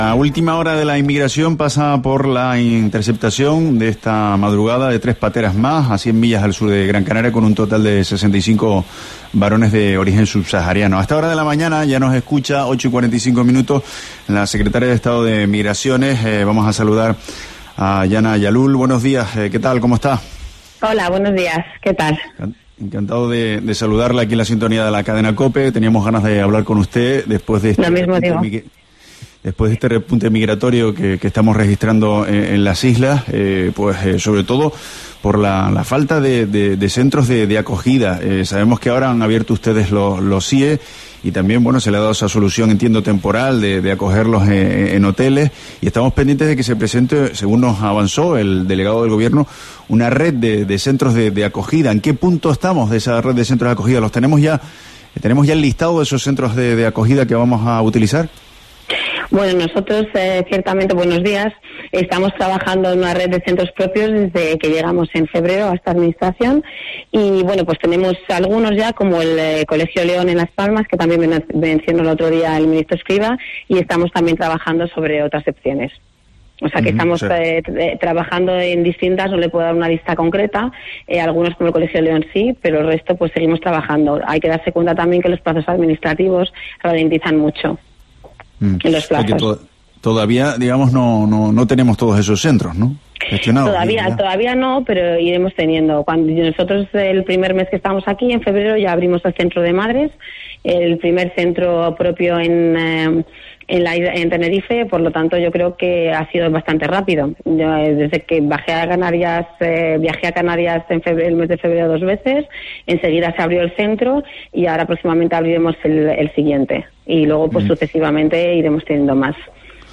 Entrevista Hana Jalloul, secretaria de Estado de Migraciones